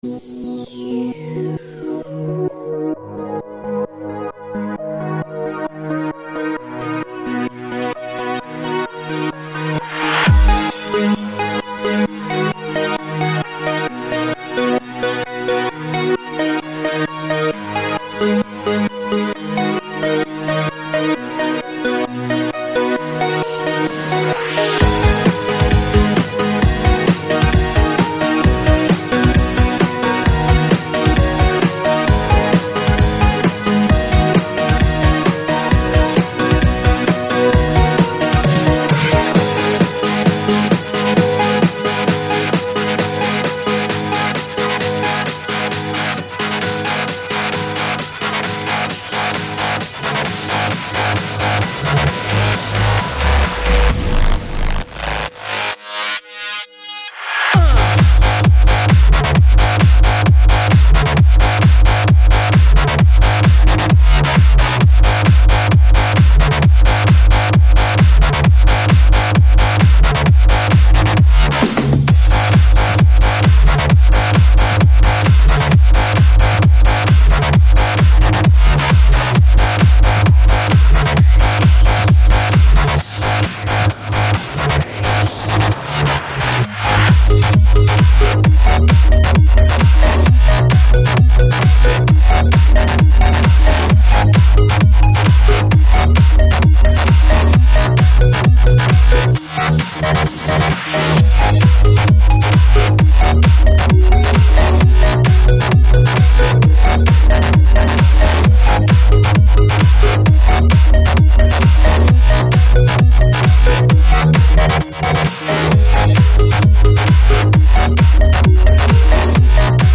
Стиль: Electro Progressive